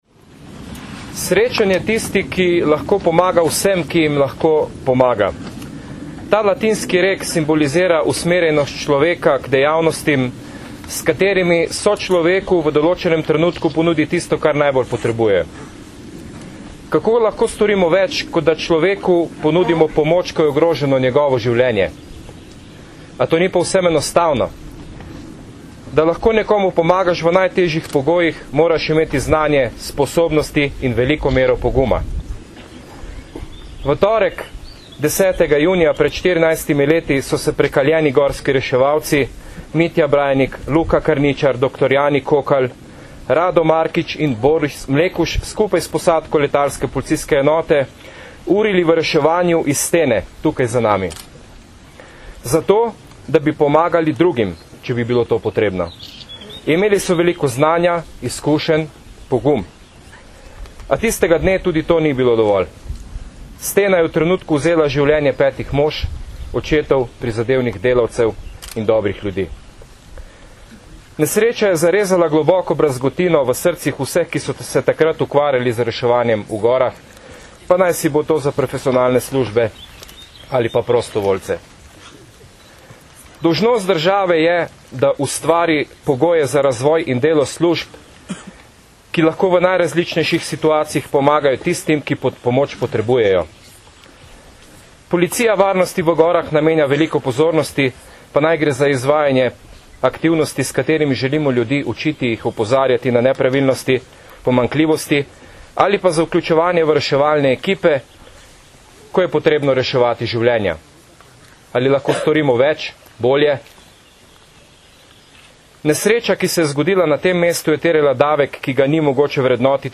Ob štirinajsti obletnici ene najhujših nesreč v zgodovini slovenskega gorskega reševanja je bil danes, 10. junija 2011, spominski pohod na Okrešelj.
Zvočni posnetek nagovora